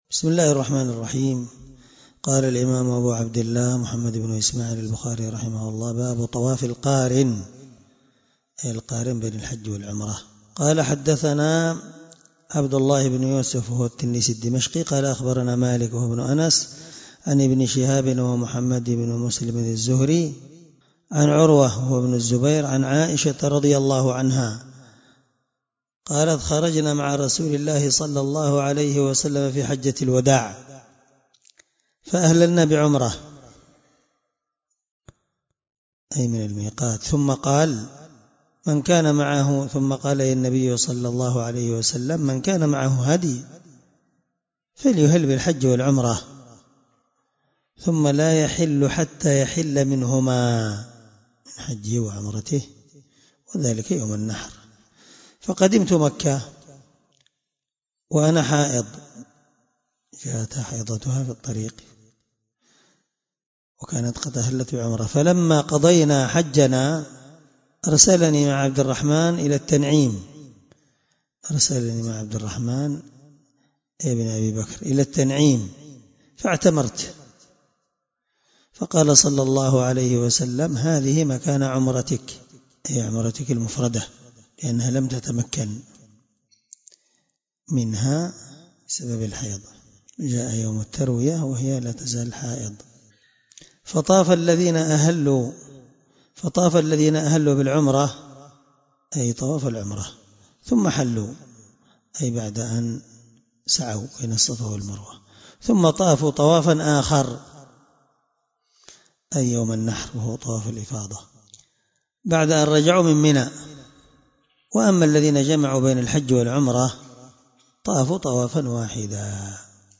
الدرس53 من شرح كتاب الحج حديث رقم(1638-1640 )من صحيح البخاري